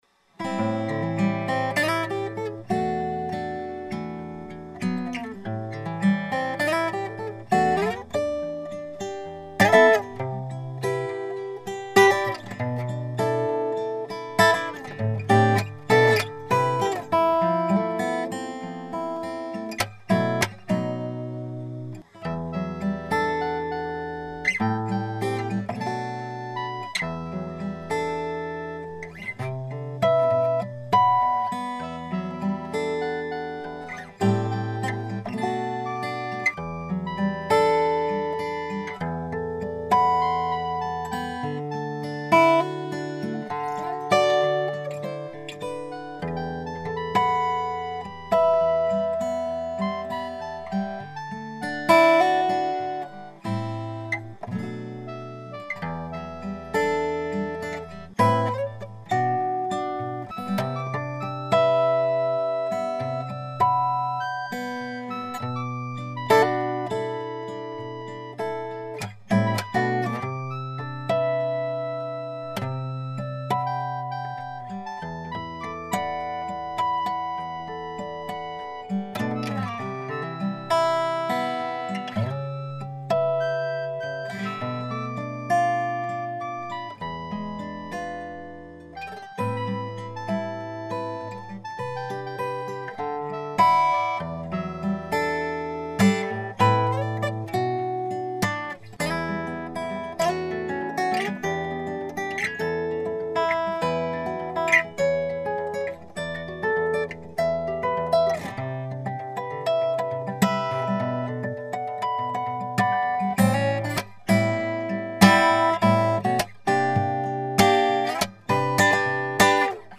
原版吉他編曲